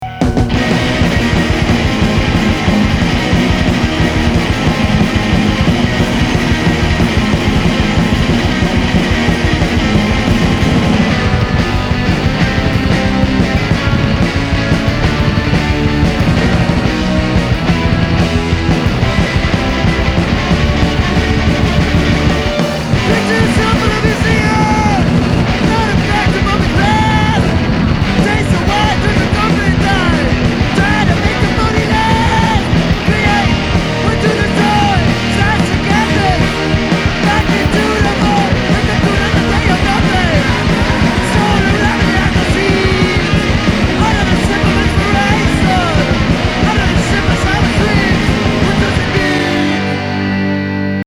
conceptual psychedlic thrash-surf explosion